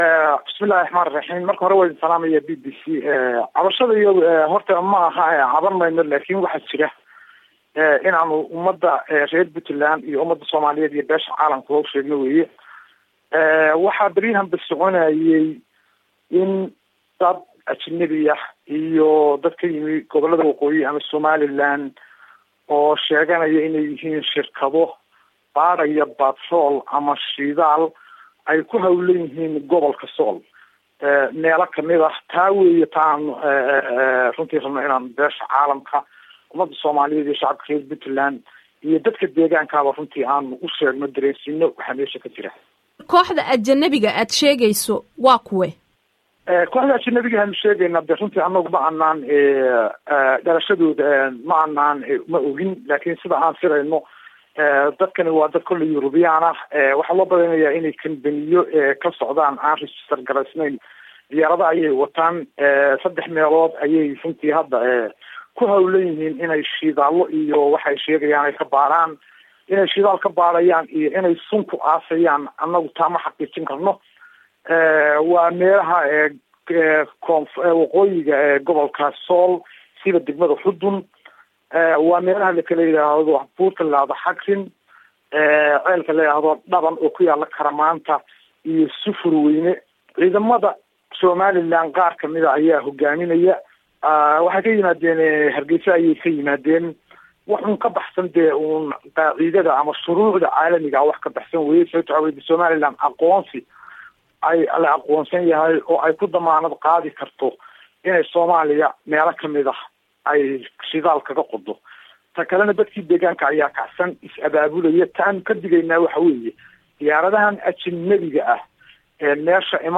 Wasiirka warfaafinta GAAS Maxamuud Xassan Soocadde, oo BBC la soo xiriiray, ayaa u Hanjabay Somaliland, isagoo ka cabanayey inay Somaliland Shidaal ka baarayso Gobpolka Sool.